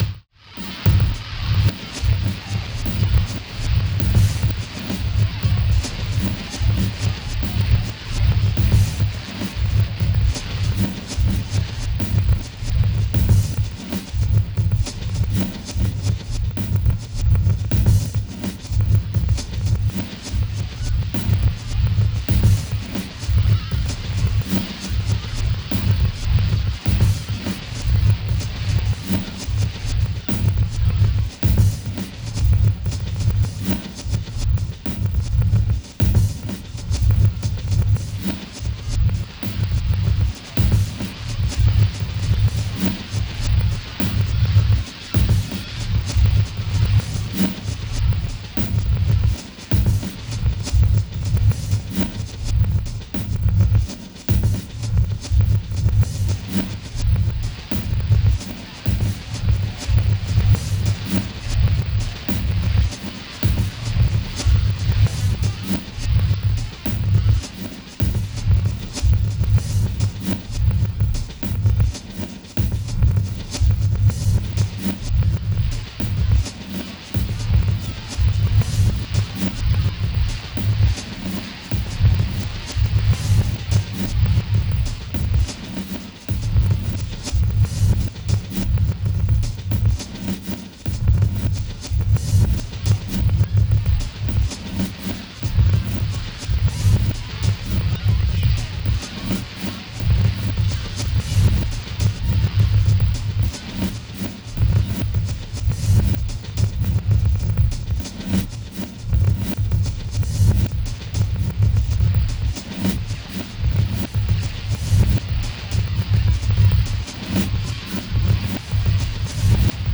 各曲ともに聴き進む内に、独特異様な酩酊感に包まれていくような...。
どうかこの不可思議な律動に身を委ね、あなたの魂を奔放に舞踏させながら、お楽しみください。